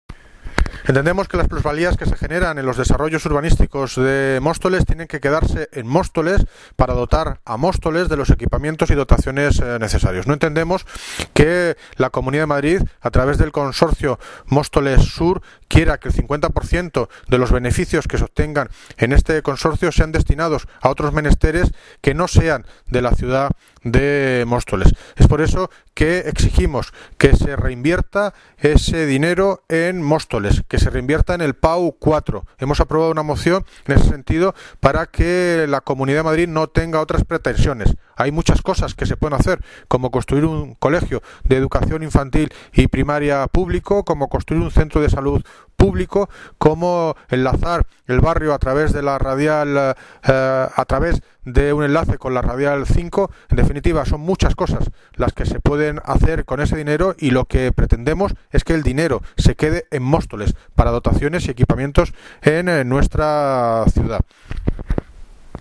Audio - David Lucas (Alcalde de Móstoles) Aprobación moción beneficios Móstoles sur